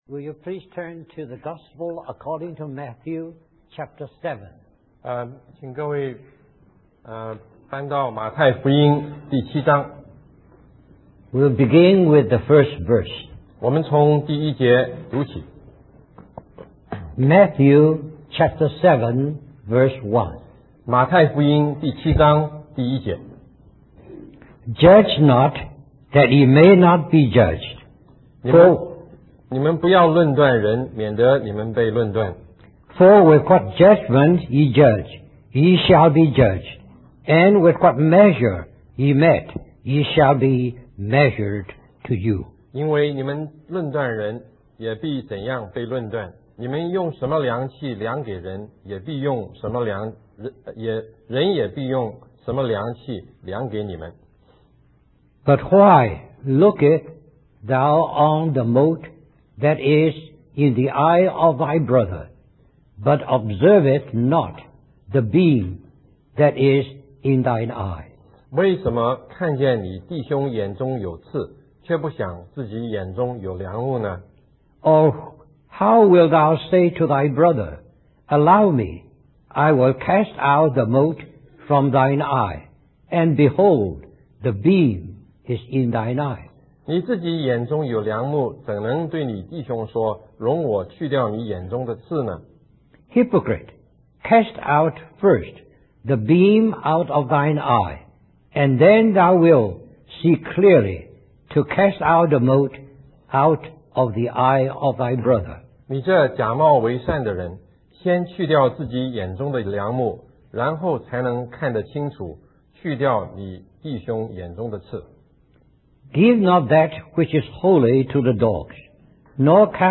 In this sermon on Matthew chapter seven, the preacher emphasizes the importance of living a disciplined and obedient life as a disciple of Jesus. The chapter covers various topics that may seem unrelated at first, but they all contribute to this central theme.